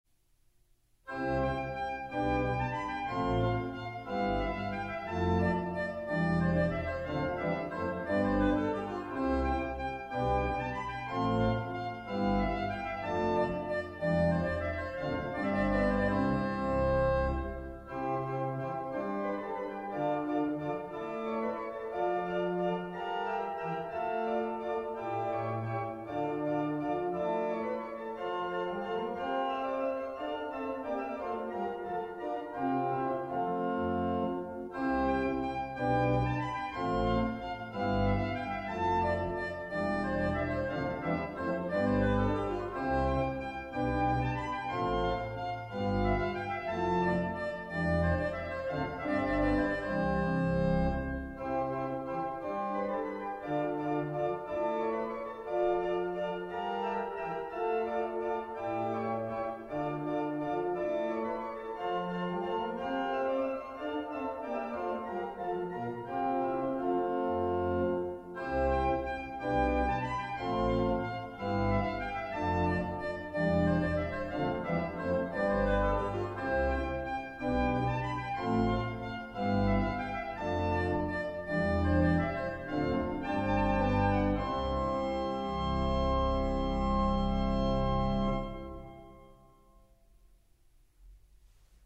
Eminent E-200 Midden eiken met 27-tonig inschuifbaar pedaal
De vier modellen uit de E serie zijn uitgevoerd met 2 klavieren, 3 geluidsgeluidskanalen en een nagalmsysteem.
Het wordt geleverd met een 27-tonig pedaal, 29 registers en een kleine setzer.